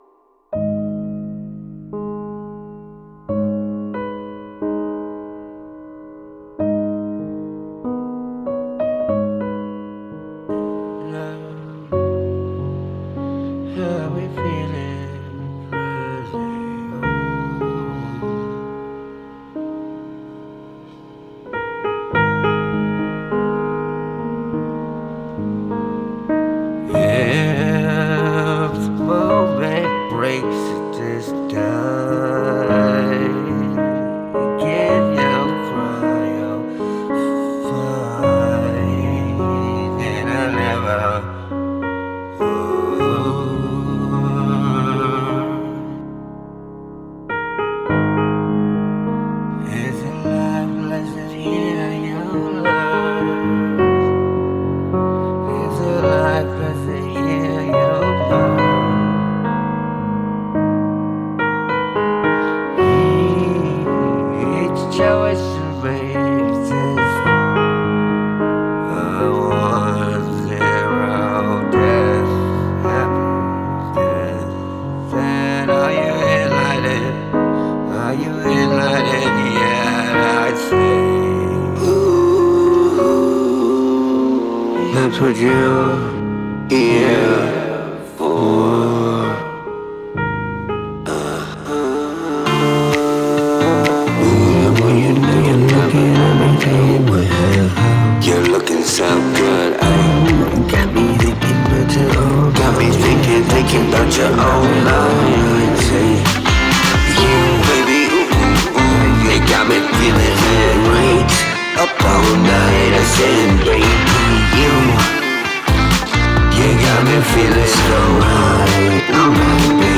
Date: 2026-02-08 · Mood: dark · Tempo: 63 BPM · Key: C major